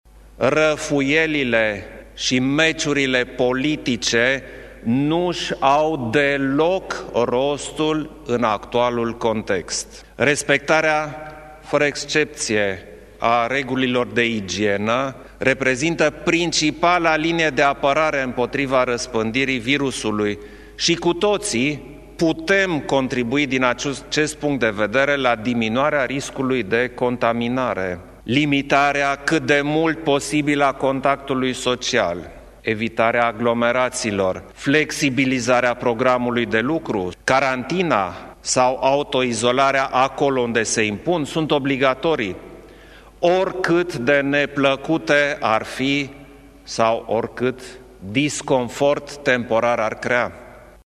Președintele Klaus Iohannis a susținut o declarație de presă la Palatul Cotroceni